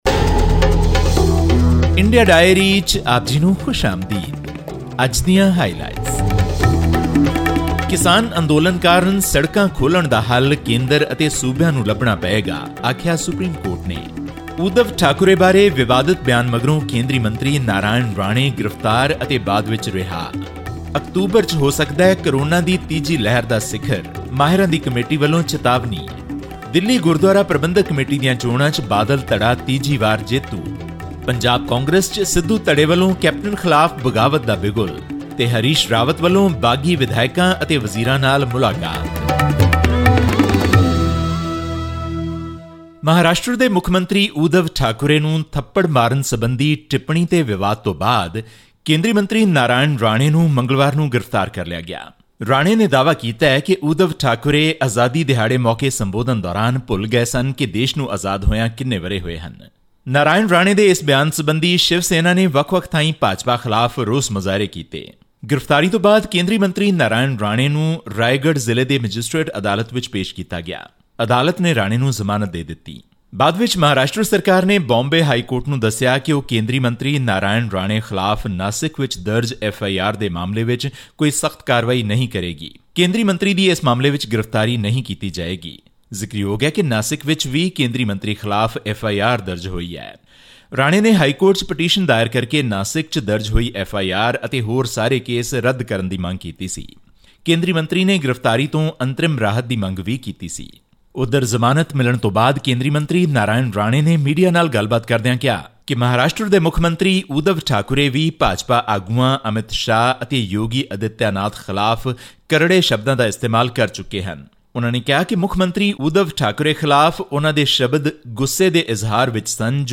The Supreme Court on 23 August said that it is the responsibility of the Centre and the states to ensure that the ongoing farmers' protests do not block movement on public roads in Delhi and adjoining regions. This and more in our weekly news segment from India.